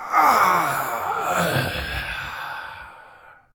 sceleton_die.ogg